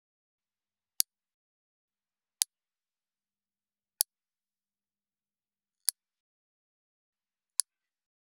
171.爪を切る【無料効果音】